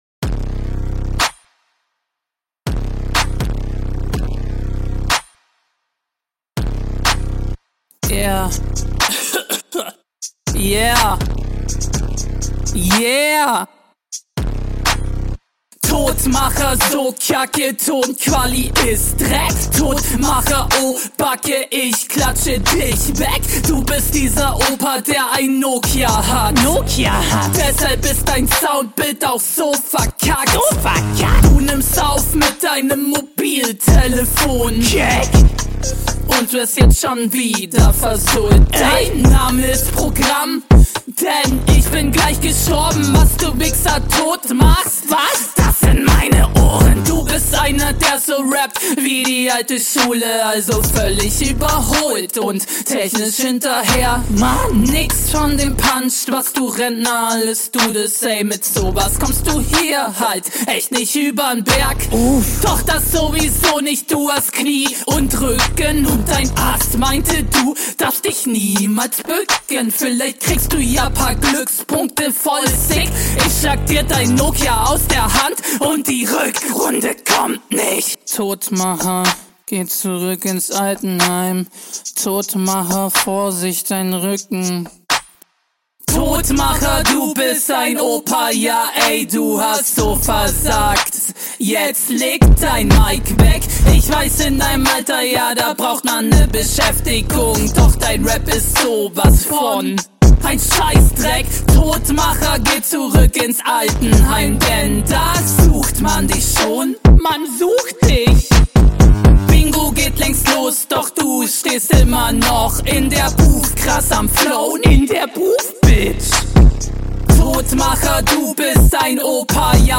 Flow: Mal was komplett anderes als ich es hier gewohnt bin.
Beatpick passt natürlich zu dir und der Stimmeneinsatz ist dementsprechend auch richtig geil.